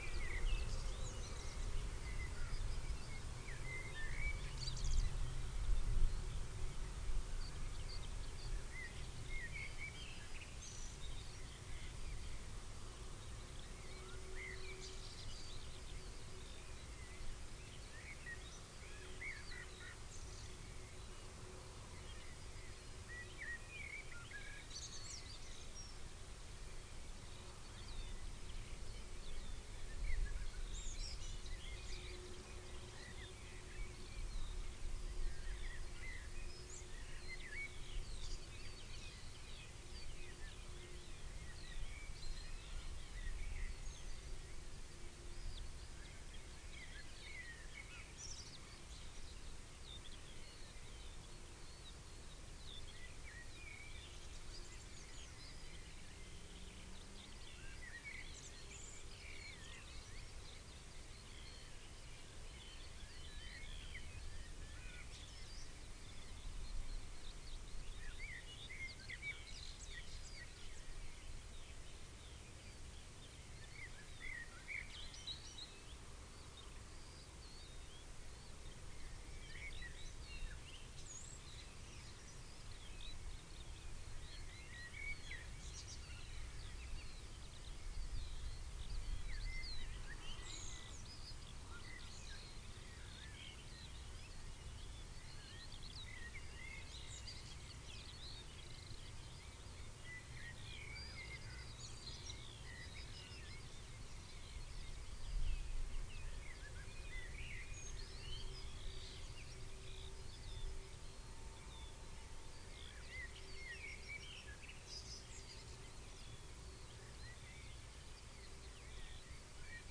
Corvus corone
Sylvia atricapilla
Turdus merula
Emberiza citrinella
Alauda arvensis